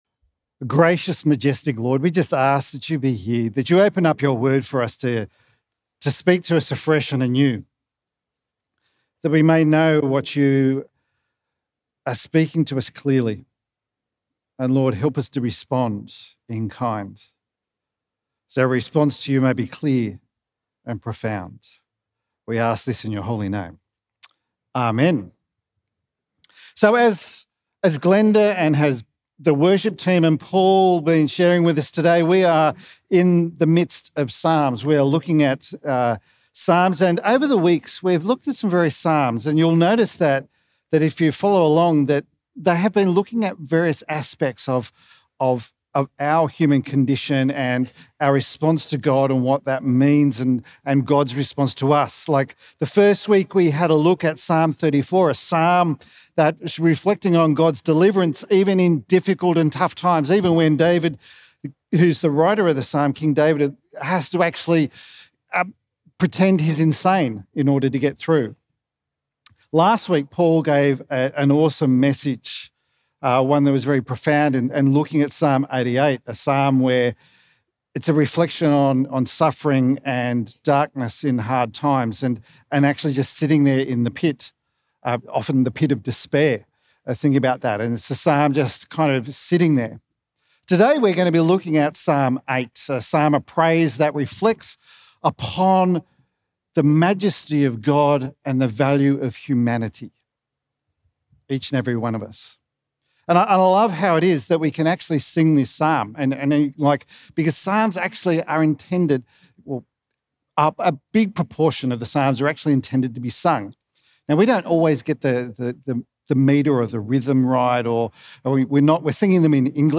Performed by the Pittwater Uniting Church band.